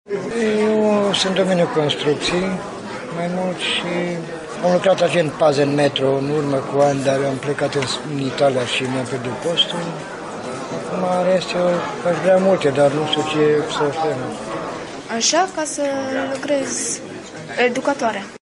vox-joburi-persoane-vulnerabile-2.mp3